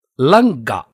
楞伽经梵音词汇读诵001-010